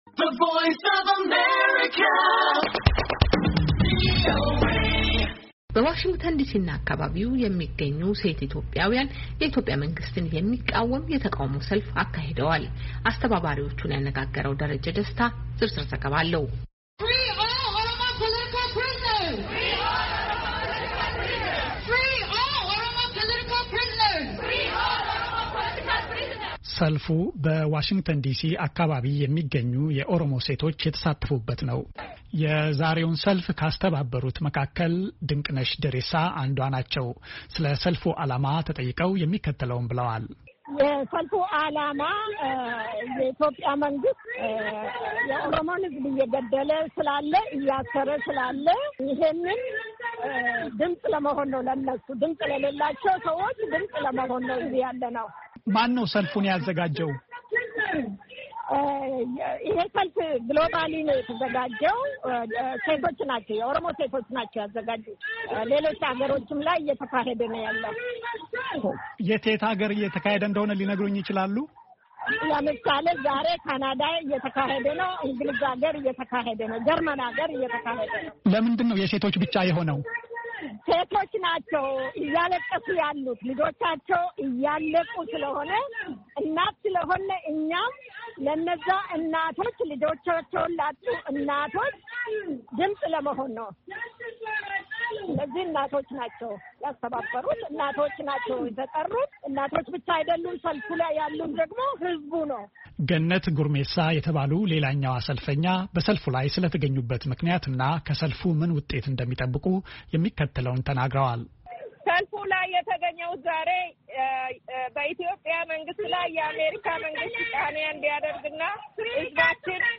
“የኦሮሞ ሴቶች ሰልፍ በዲሲ” በሚል የተጠራው የቃውሞ ሰልፍ በዛሬው ዕለት በዋሽንግተን ዲሲ የውጭ ጉዳይ ሚኒስትር ጽ/ቤት ፊት ለፊት ተካሂዷል፡፡ የሰልፉ ዓላማ ድምጽ ለሌላቸው የኦሮሞ ሴቶች ድምጽ ለመሆን ነው” ያሉት ሰለፈኞቹ እስረኞች ይፈቱ፣ የኦሮሞ ሴቶችን መድፈር ይቁም የኢትዮጵያ መንግሥት የኦሮምን ህዝብ መግደል ያቁም!